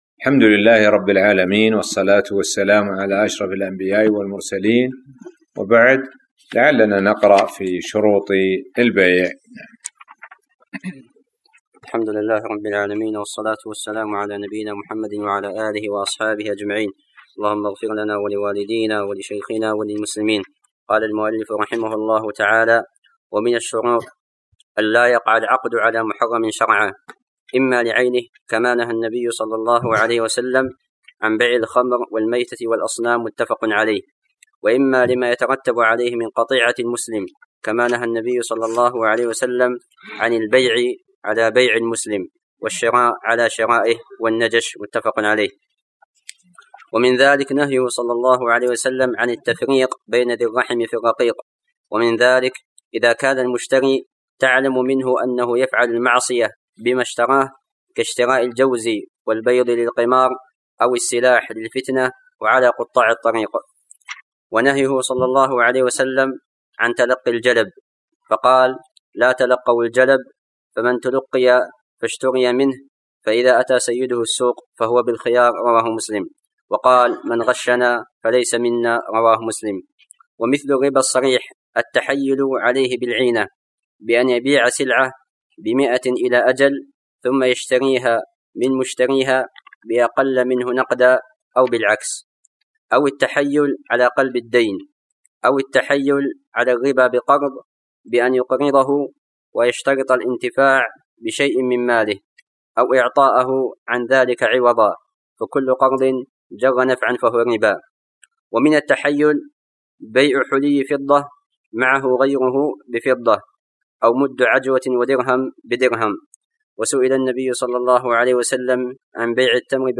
الدرس--38 [تابع شروط البيع]